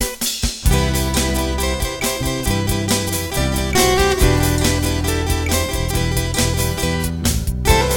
Músico, Arreglista, Guitarrista y Compositor